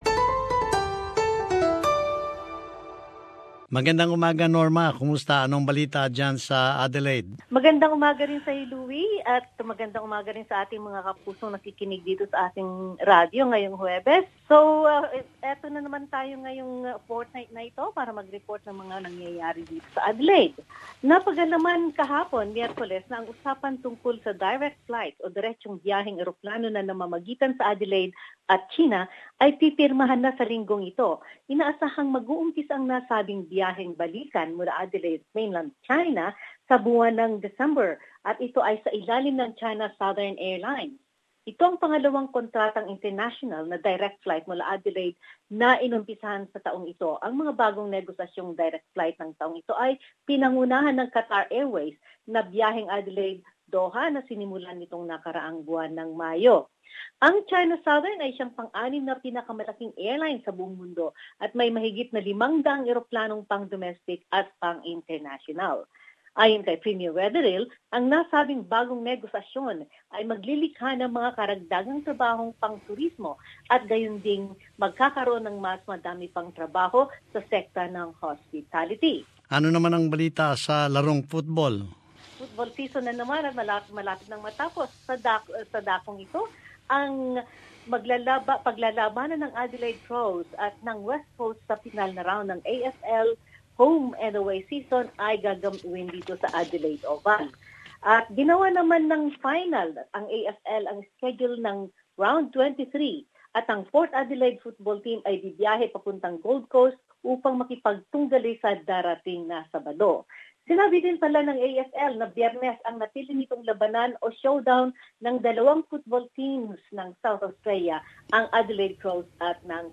South Australia News.